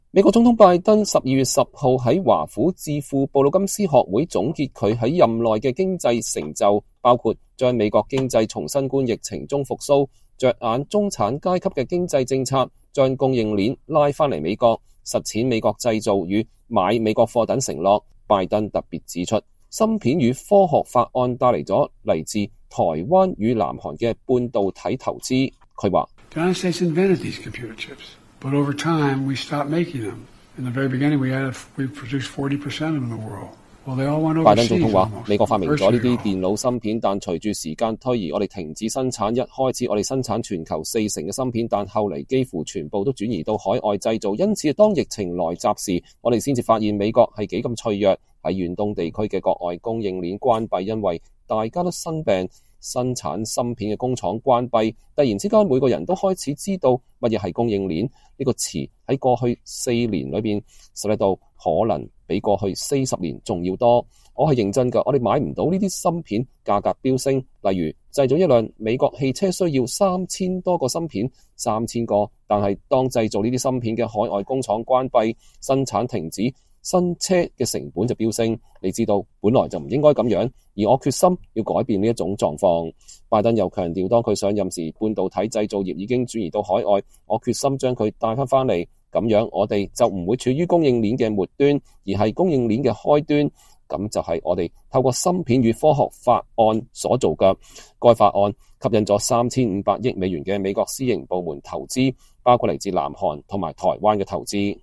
美國總統拜登12月10日在華府智庫布魯金斯學會總結他在任的經濟成就，包括把美國經濟從新冠疫情中復蘇，著眼中產階級的經濟政策，將供應鏈拉回美國，實踐美國製造與買美國貨等承諾。拜登特別指出《芯片與科學法案》帶來了來自台灣與南韓的半導體投資。